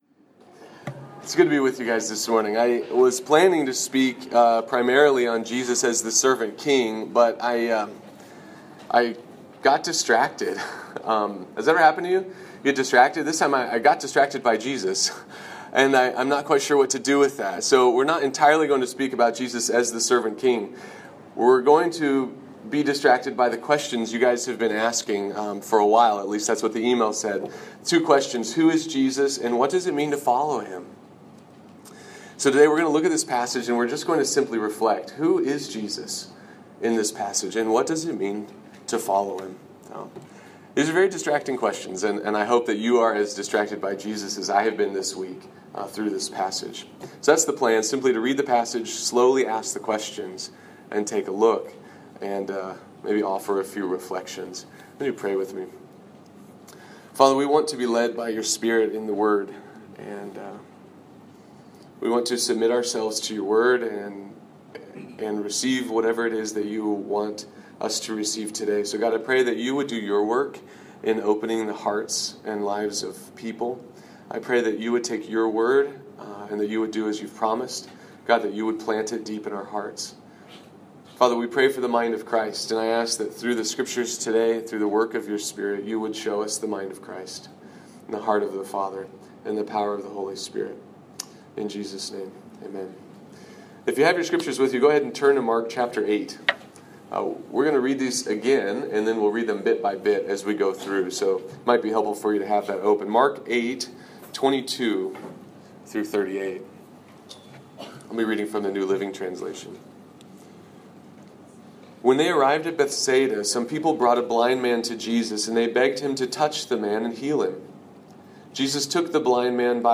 I am beginning to link past sermons to our new podcast channel on iTunes